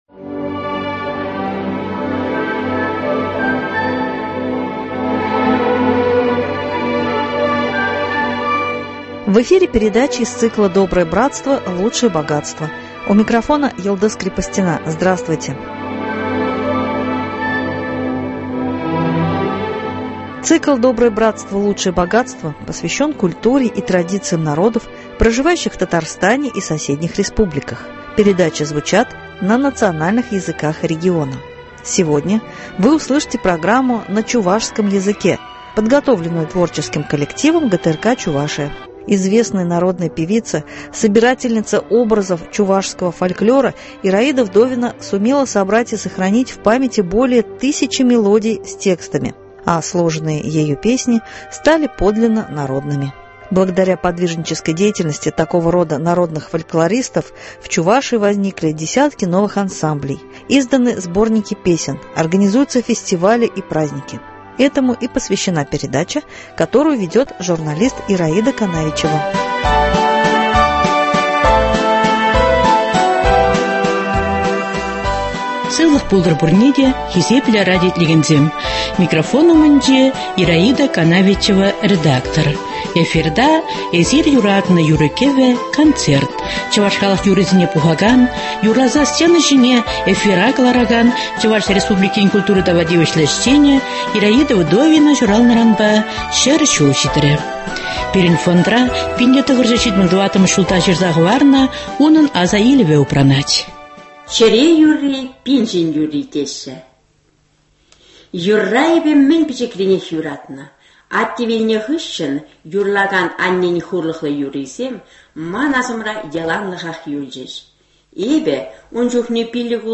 Благодаря подвижнической деятельности такого рода народных фольклористов в Чувашии возникли десятки новых ансамблей, изданы сборники песен, организуются фестивали и праздники (передача из фондов радио,на чувашском языке).